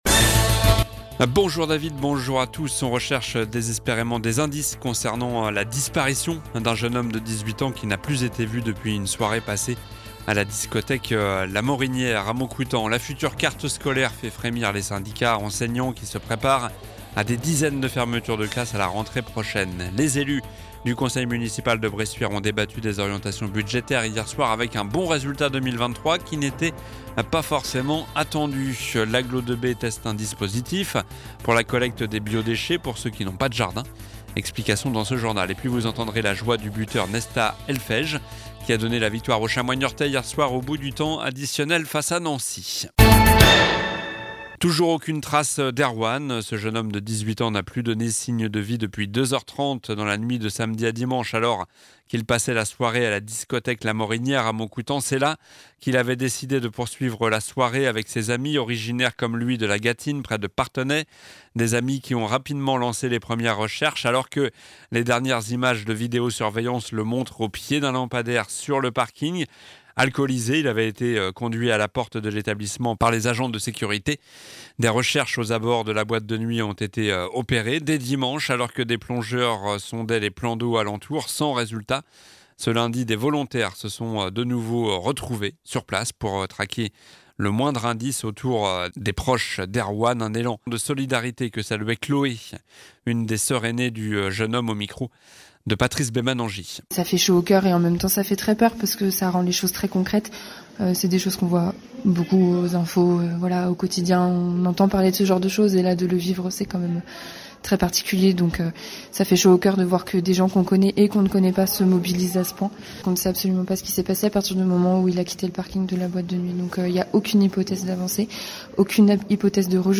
Journal du mardi 13 février (midi)